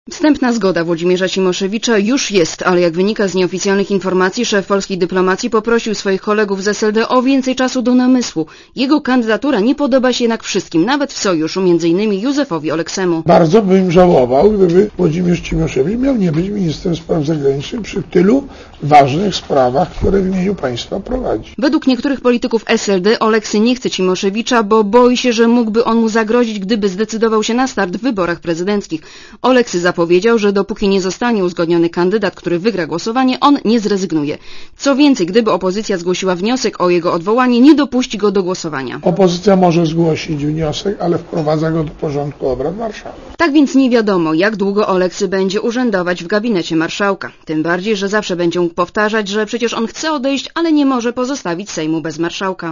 Relacja reportera Radia Zet Rozważam całość sytuacji.